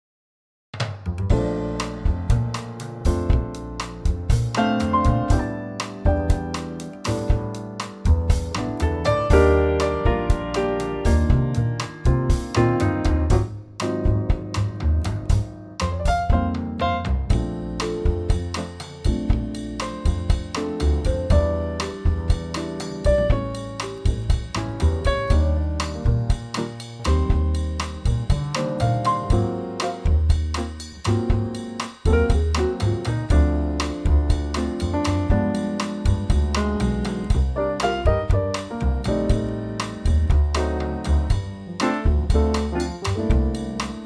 Unique Backing Tracks
key - Bb - vocal range - Db to Ab
Brighter Trio arrangement of this Bossa classic